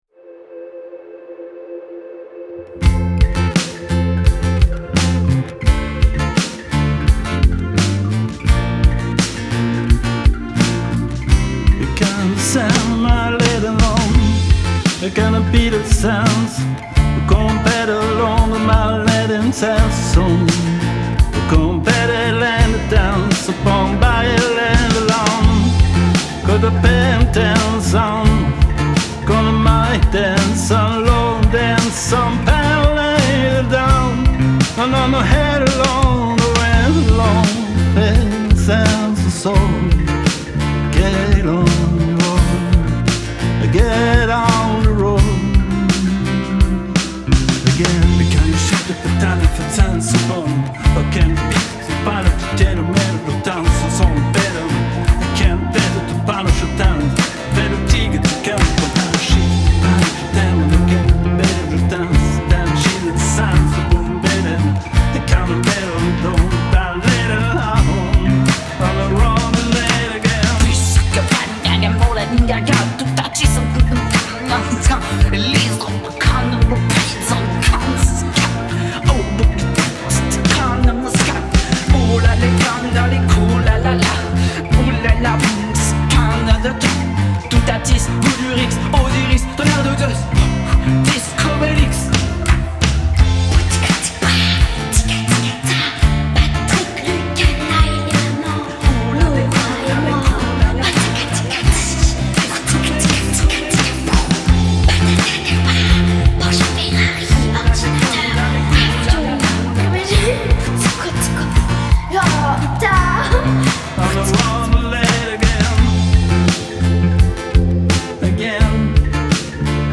Et voici la dernière song de 2025 , "Les ames vagabondes"(Bug Reboot), une petite chanson légère et optimiste dont la genèse a été la visite du studio Monmix par des amis durant laquelle il a été décidé de se lancer le challenge de faire une chansons quasiment à la volée dans le studio.
Une chanson pop légère américaine a été suggérée concernant le style.
version yaourt telle que sortie de la seance studio)